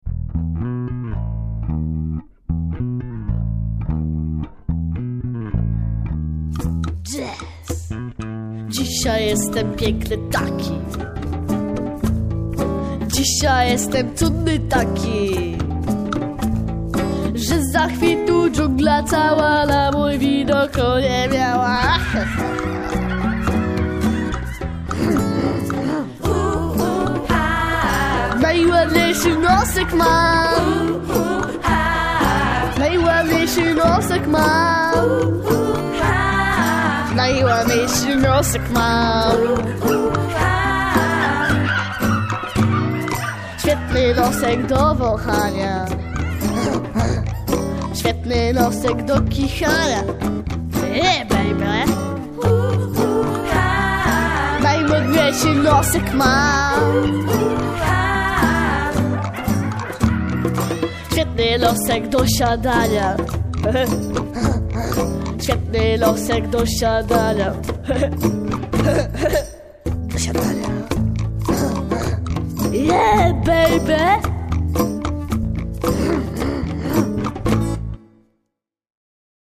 Pojawiły się tam trzy piosenki ze spektakli teatralnych.
oraz z przedstawienia  HUMBA, BUMBA, BANG!, które zostało przygotowane przez dzieci podczas wakacyjnych warsztatów teatralnych w szczecińskiej Pleciudze